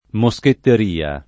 moschetteria [ mo S ketter & a ] s. f.